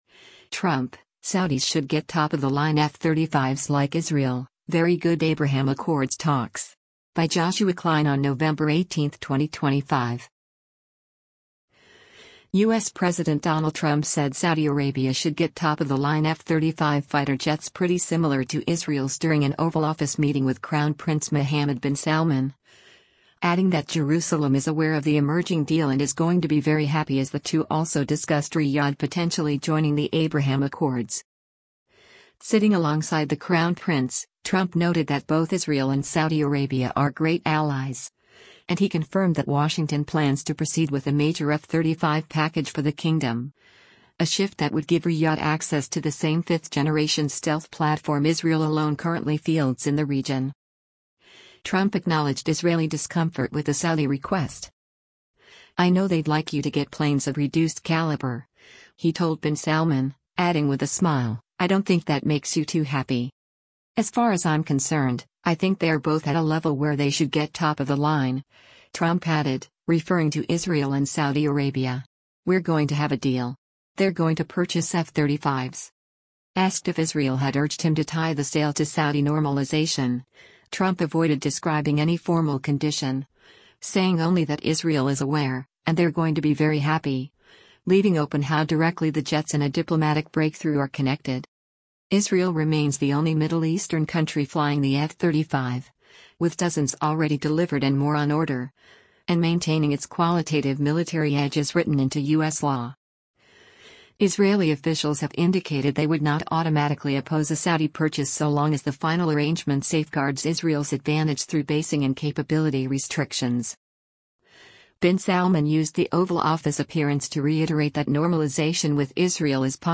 U.S. President Donald Trump said Saudi Arabia “should get top of the line” F-35 fighter jets “pretty similar” to Israel’s during an Oval Office meeting with Crown Prince Mohammed bin Salman, adding that Jerusalem is “aware” of the emerging deal and “is going to be very happy” as the two also discussed Riyadh potentially joining the Abraham Accords.